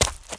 High Quality Footsteps
STEPS Leaves, Run 10.wav